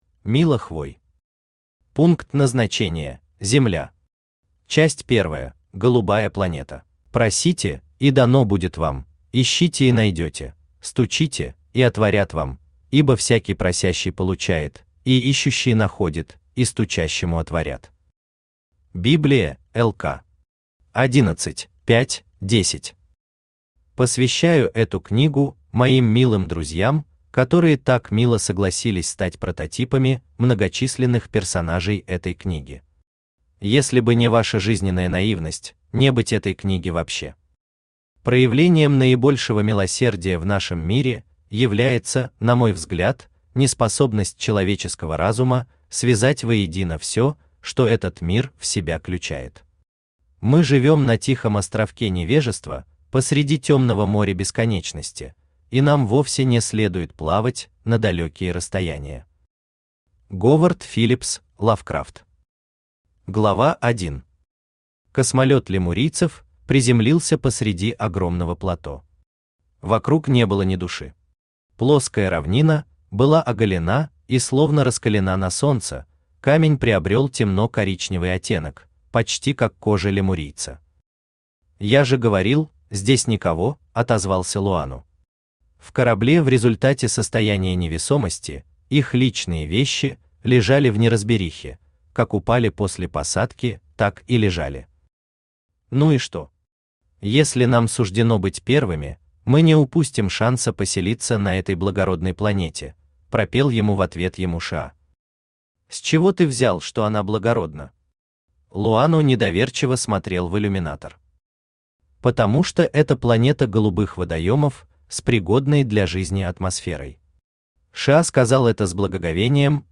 Аудиокнига Пункт Назначения: Земля. Часть первая: Голубая планета | Библиотека аудиокниг
Часть первая: Голубая планета Автор Мила Хвой Читает аудиокнигу Авточтец ЛитРес.